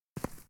footstep_earth_right.ogg